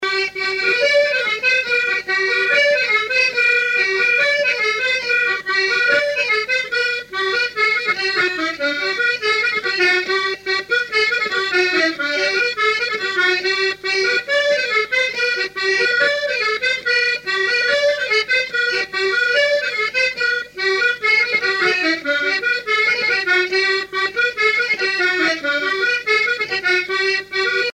danse : branle : courante, maraîchine
musique à danser à l'accordéon diatonique
Pièce musicale inédite